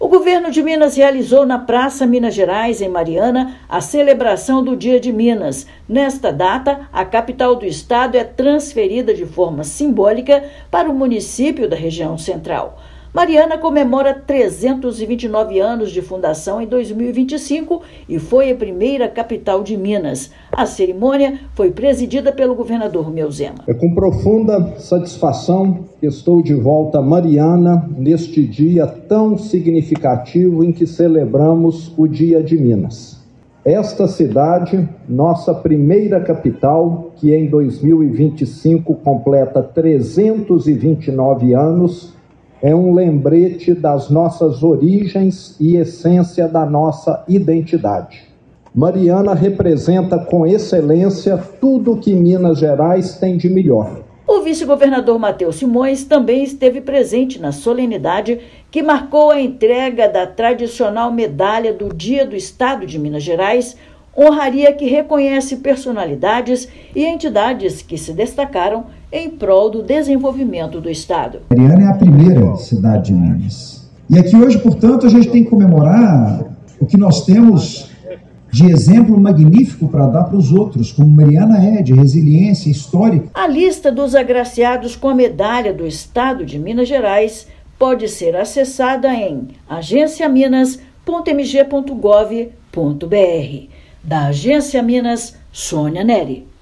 [RÁDIO] Governo do Estado entrega Medalha do Dia de Minas, em Mariana
Honraria homenageia personalidades e entidades que contribuíram para o desenvolvimento do estado. Ouça matéria de rádio.